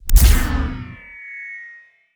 SCIEnrg_Shield Activate_03_SFRMS_SCIWPNS.wav